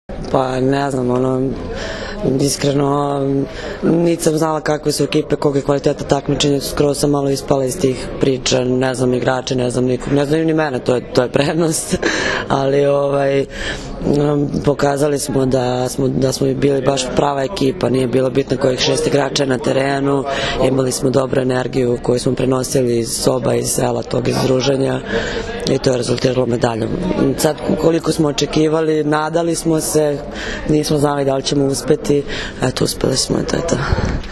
IZJAVA MARTE DRPE